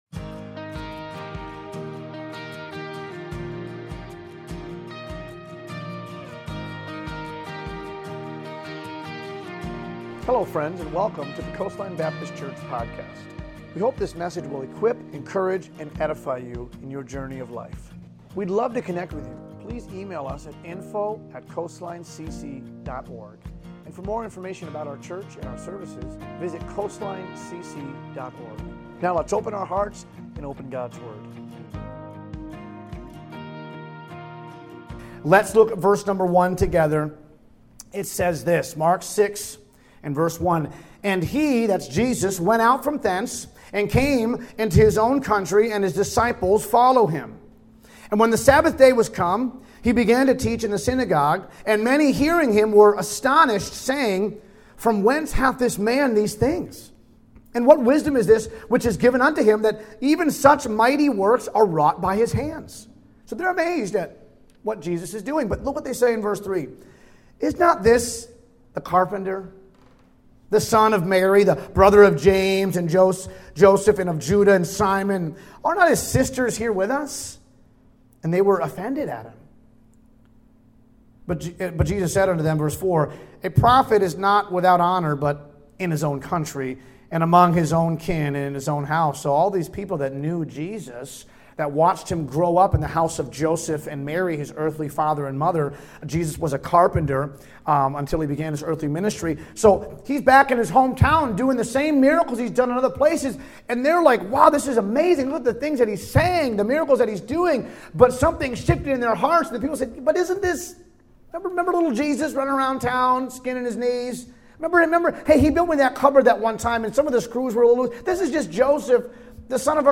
Home Discover Coastline Our Ministries Our Location Plan Your Visit Sermons Church Online Giving Contact Us I Believe In Miracles September 7, 2025